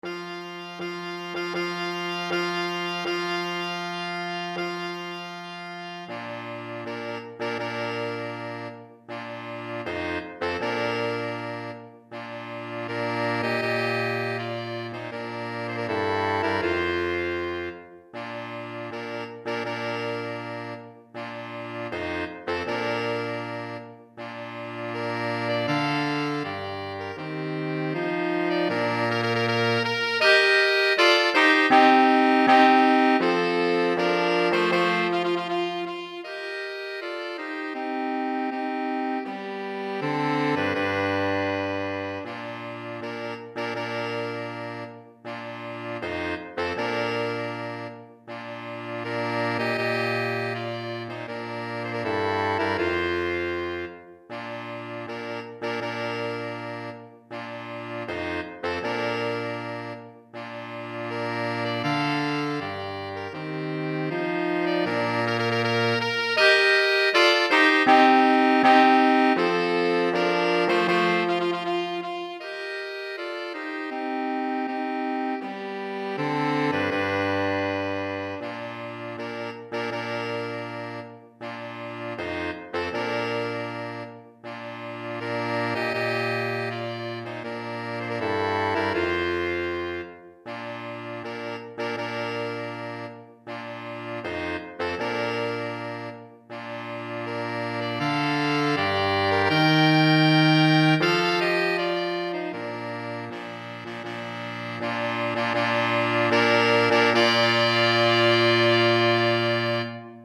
3 Saxophones